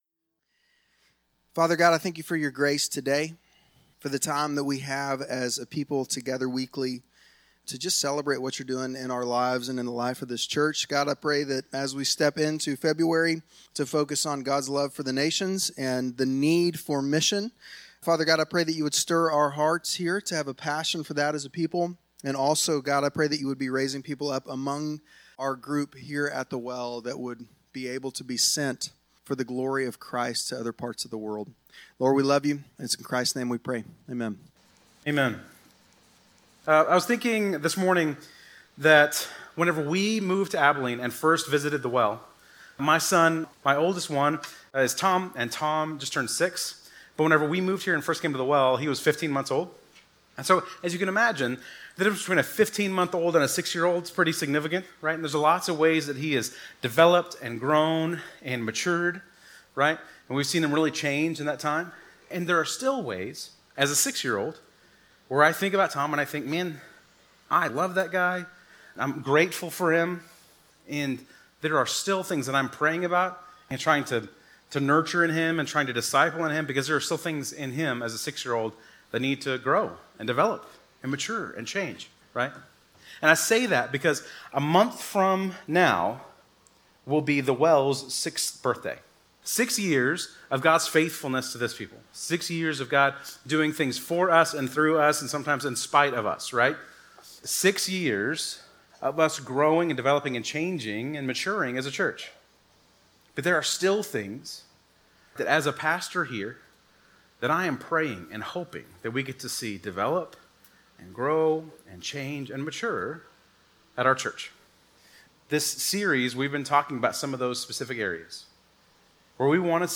Bible Text: Luke 24:44-49 | Preacher